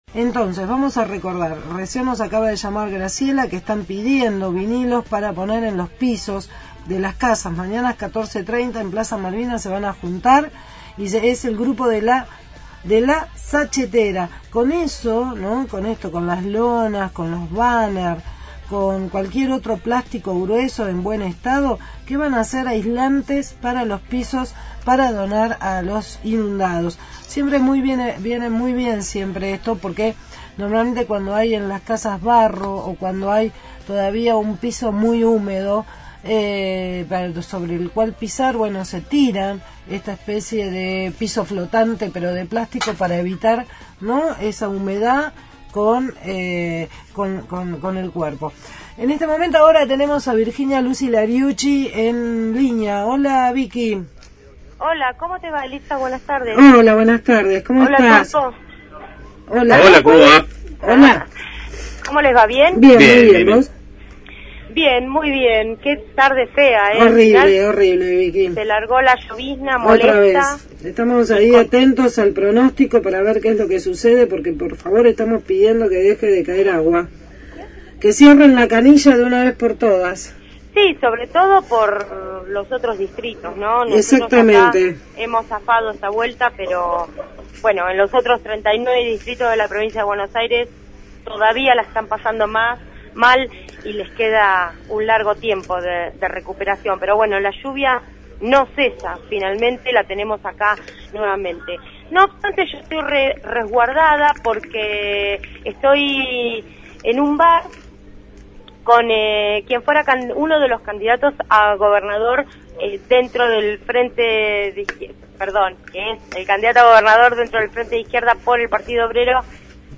MÓVIL/ Nestor Pitrola solicitud apertura de urnas – Radio Universidad